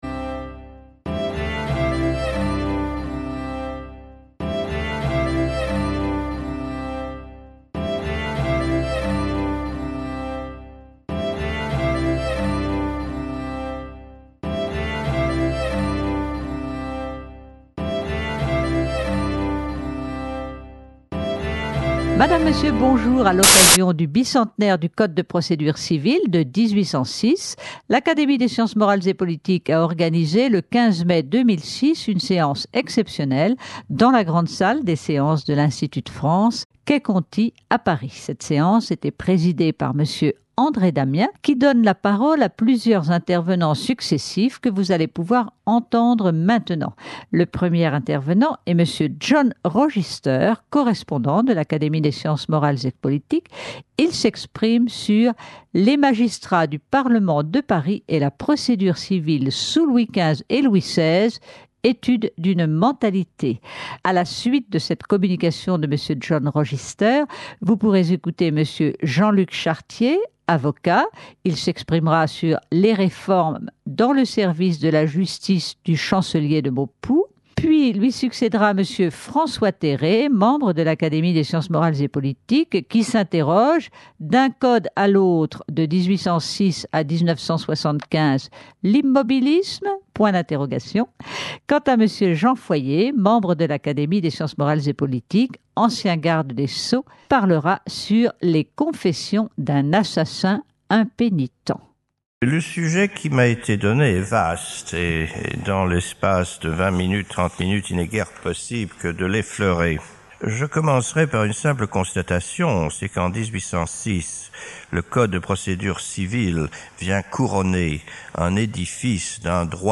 Le 15 mai 2006, à l’occasion du bicentenaire du Code de Prodédure civile de 1806, l’Académie des sciences morales et politiques a tenu une séance exceptionnelle.